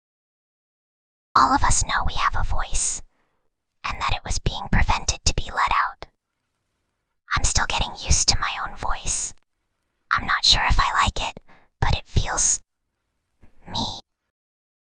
File:Whispering Girl 8.mp3
Whispering_Girl_8.mp3